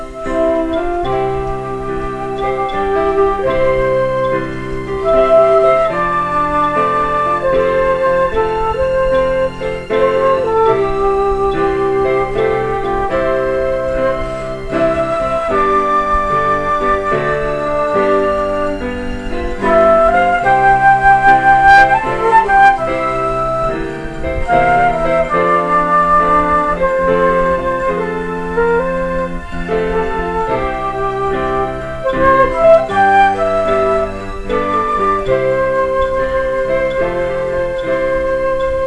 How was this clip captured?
Saya rekod tanga malam.